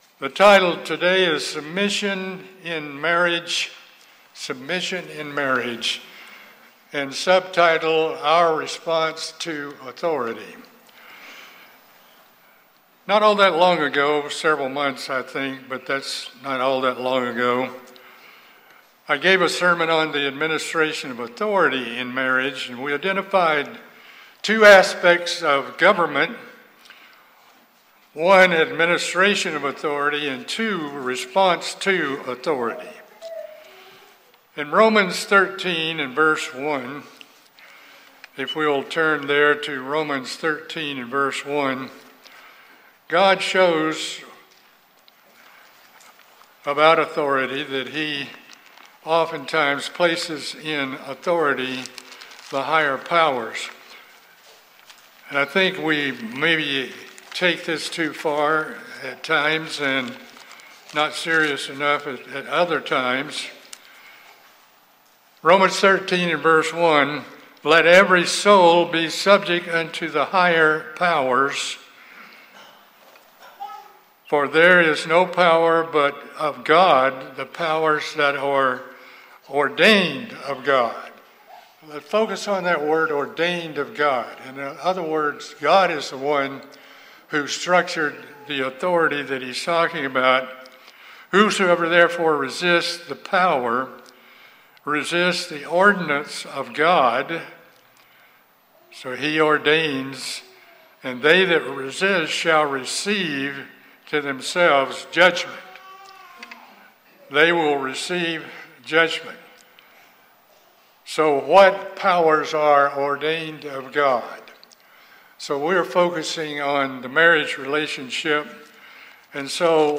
This sermon discusses the necessity of a submissive heart by men and women. And it focuses on God’s hierarchical structure for marriage with the structure being: God, Christ, man, women and children.